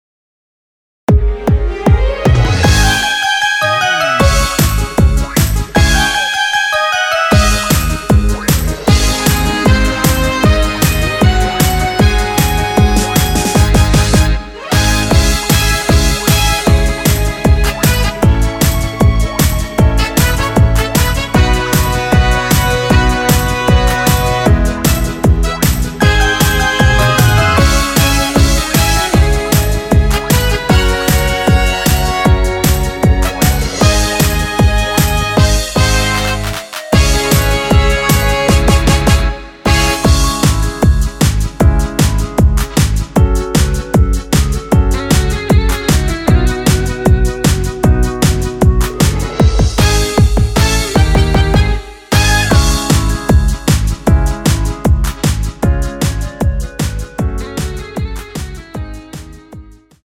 원키에서(-1)내린 멜로디 포함된 MR입니다.
Dbm
앞부분30초, 뒷부분30초씩 편집해서 올려 드리고 있습니다.
중간에 음이 끈어지고 다시 나오는 이유는